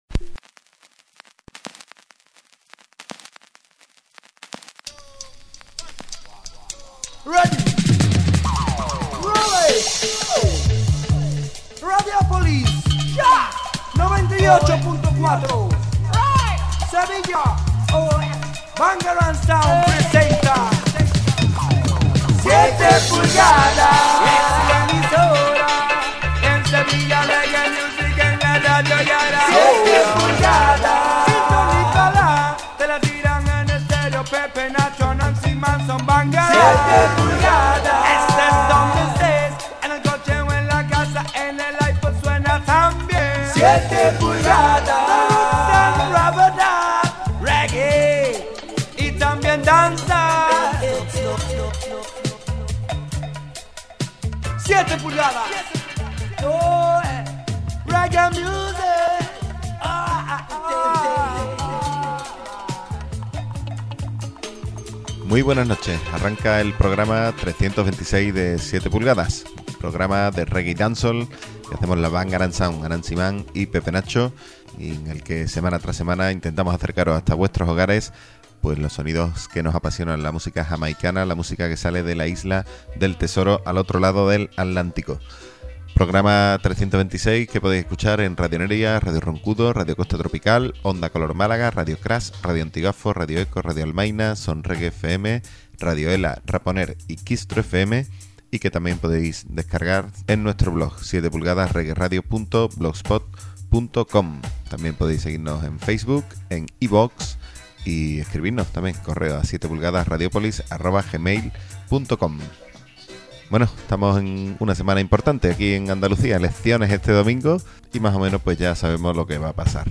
Esta semana os traemos una nueva mixtape.